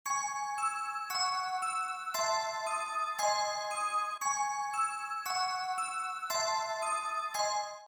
Download Terror sound effect for free.